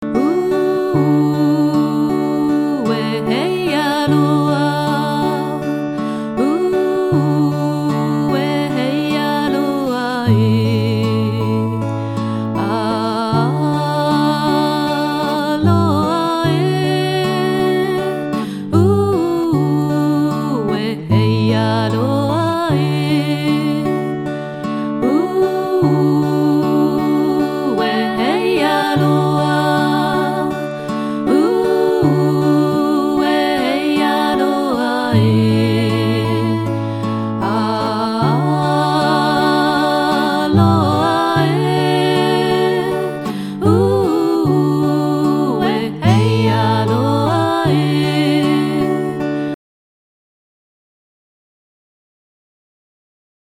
Hawaiianisches Begrüssungslied